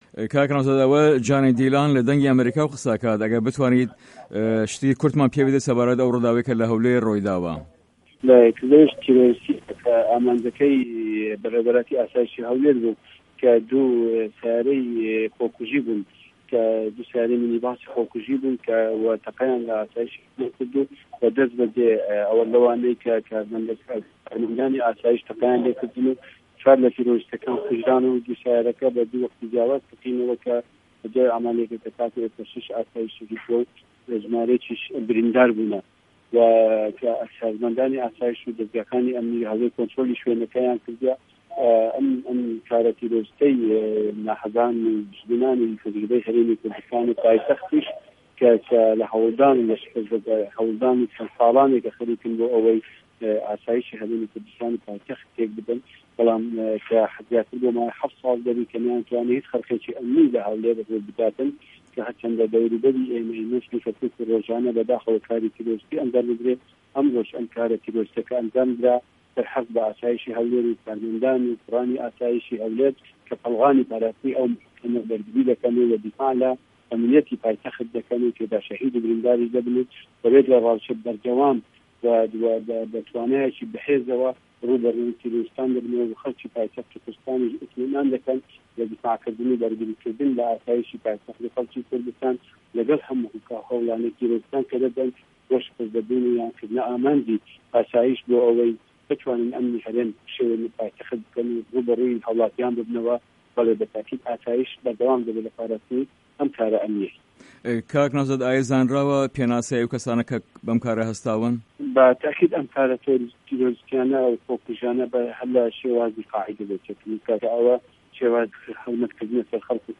وتووێژی نه‌وزاد هادی